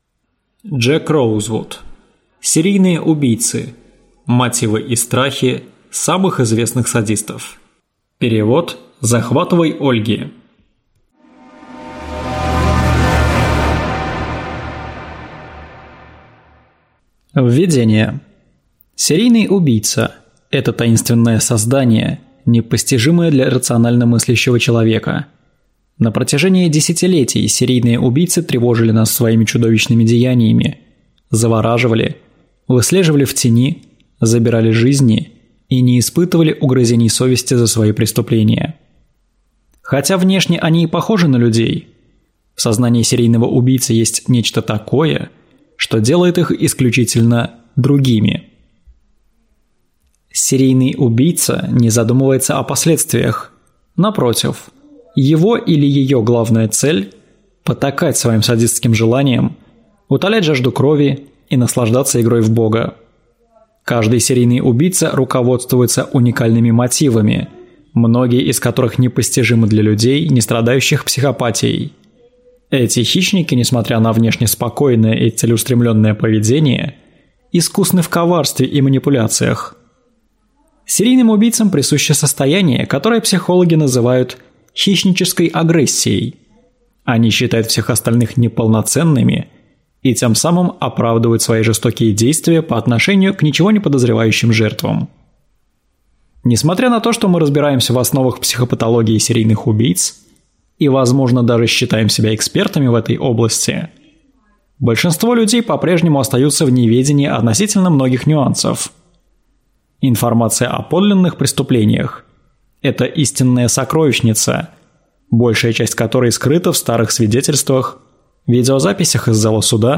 Аудиокнига Серийные убийцы. Мотивы и страхи самых известных садистов | Библиотека аудиокниг